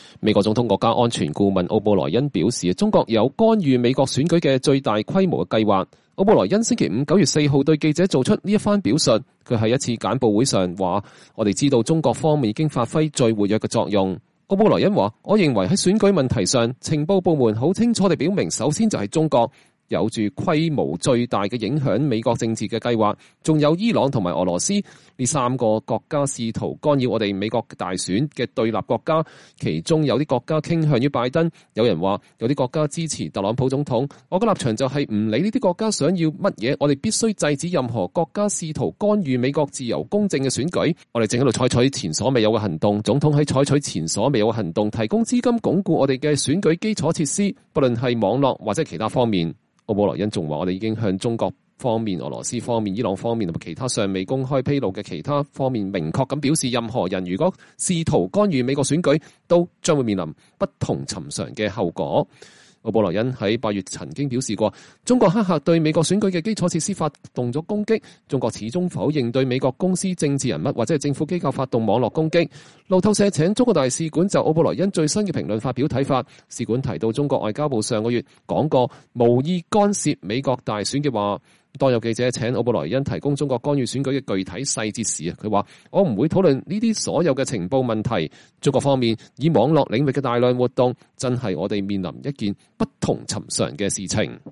美國總統國家安全顧問奧布萊恩表示，中國有干預美國選舉的最大規模的計劃。奧布萊恩星期五(9月4日)對記者做出了這番表述。他在一次簡報會上說：“我們知道中國方面已經發揮了最活躍的作用。”